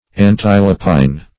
Definition of antilopine.
Search Result for " antilopine" : The Collaborative International Dictionary of English v.0.48: Antilopine \An*til"o*pine\ ([a^]n*t[i^]l"[-o]*p[imac]n), a. Of or relating to the antelope.